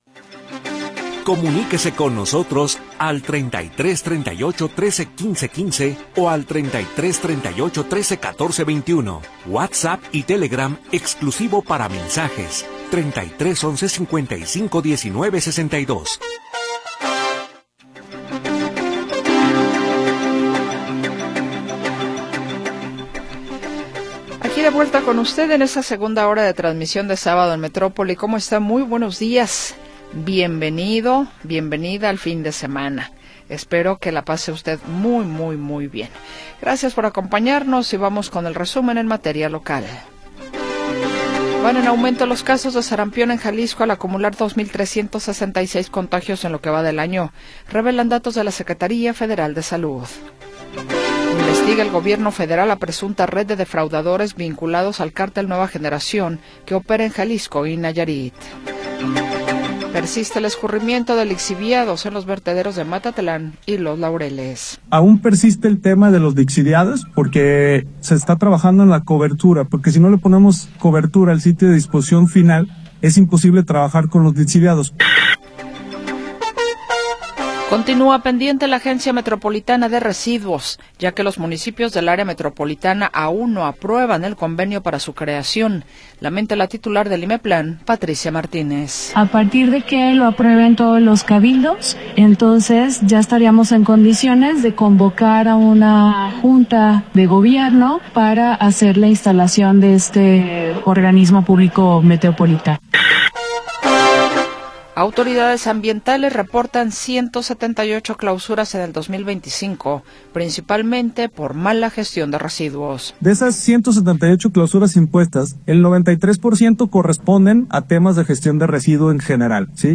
Segunda hora del programa transmitido el 21 de Febrero de 2026.